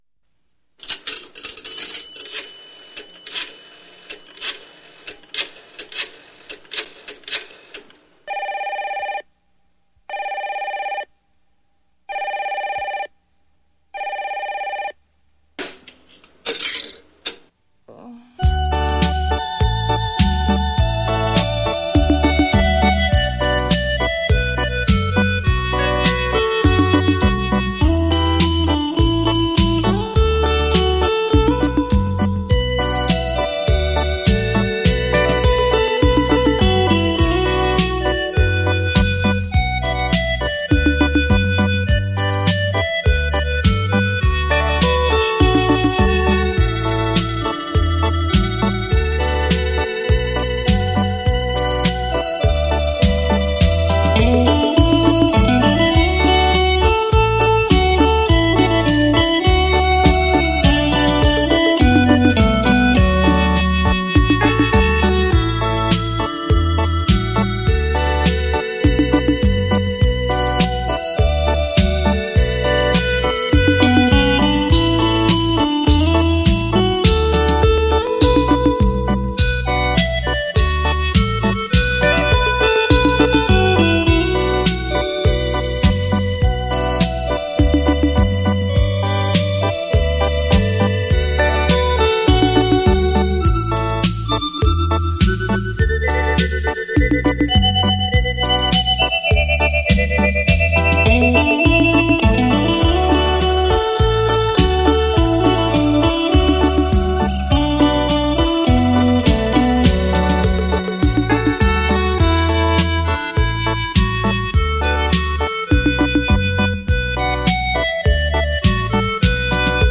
HiFi立体音响 3
电子音效满场飞
全场环绕最高境界